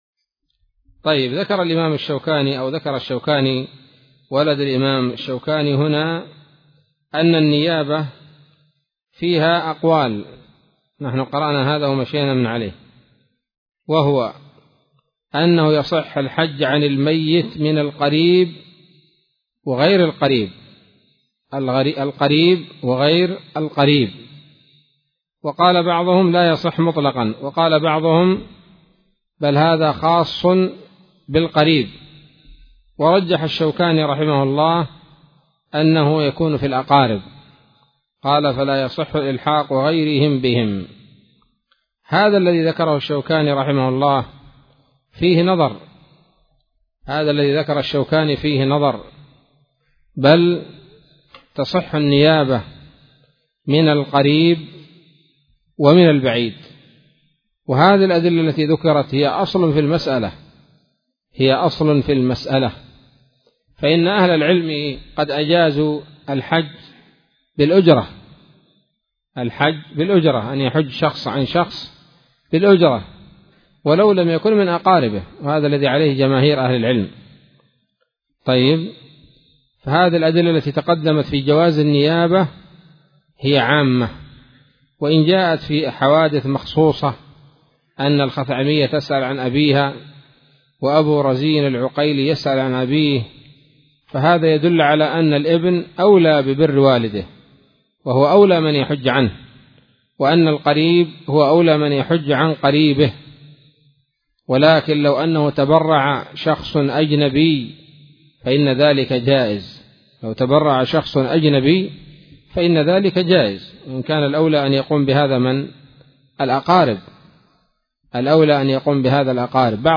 الدرس الثاني من كتاب الحج من السموط الذهبية الحاوية للدرر البهية